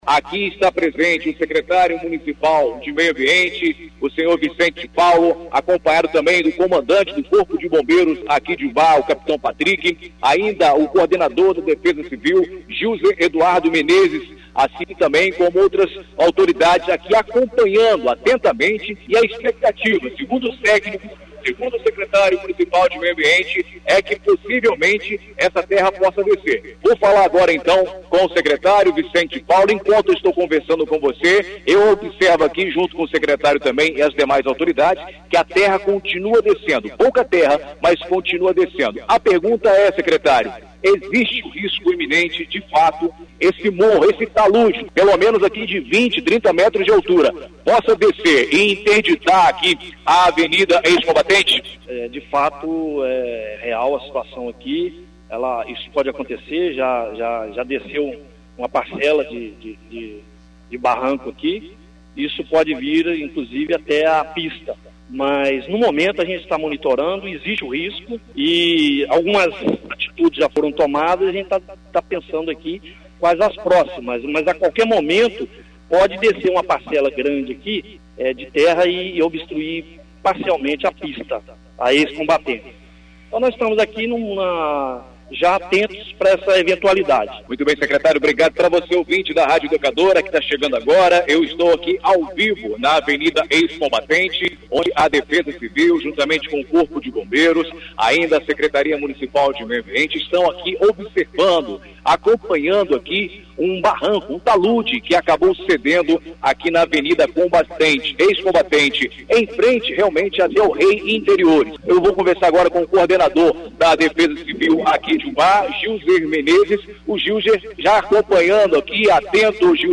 CLICK NO PLAY E OUÇA AS AUTORIDADES
ENTREVISTA EXIBIDA NA RÁDIO EDUCADORA AM/FM UBÁ – MG